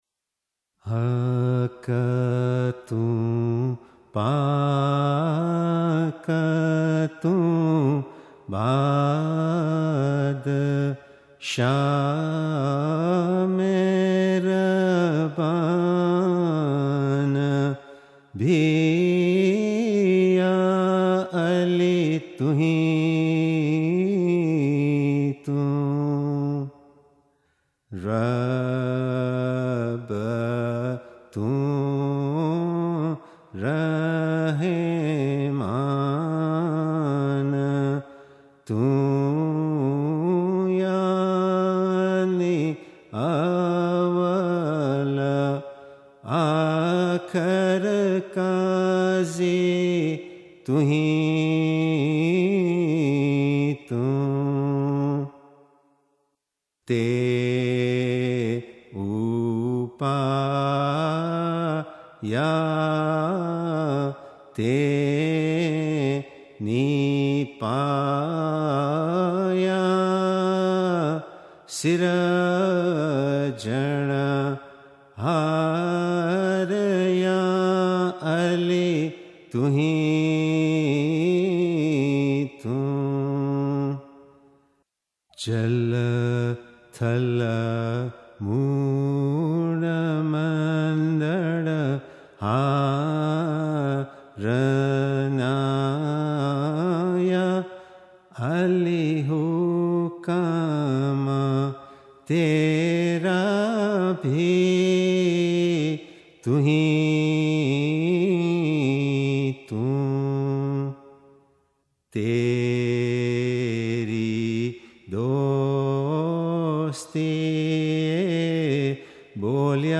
Ginan: Haq to paak tu baadshaah – You are the Truth and the Pure Majestic King – Ismailimail